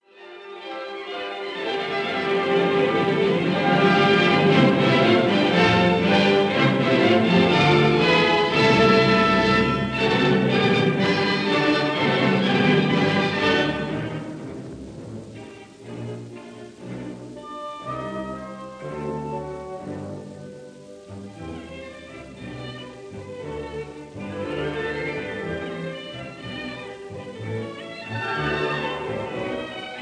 Allegro vivace